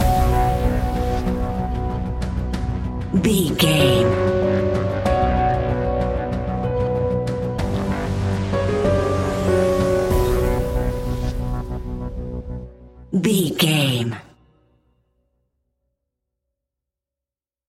Aeolian/Minor
ominous
eerie
drums
synthesiser
horror piano